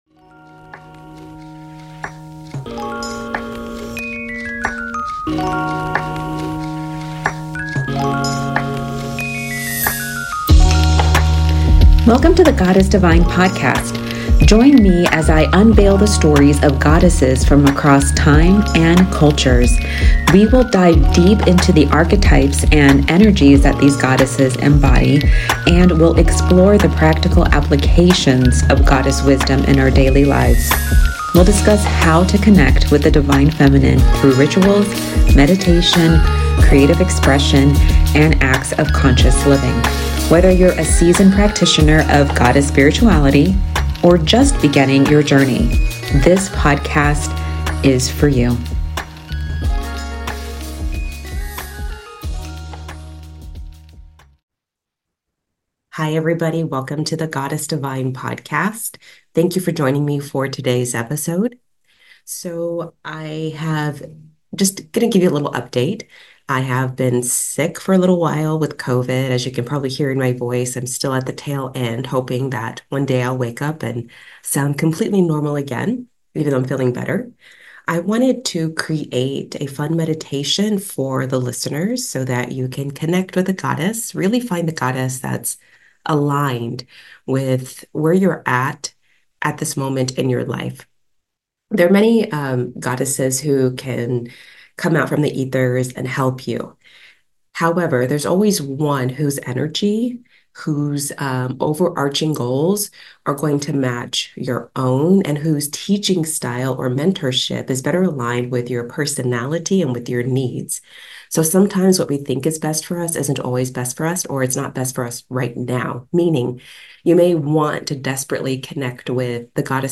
Today's episode includes a meditation to meet a goddess aligned with your higher self.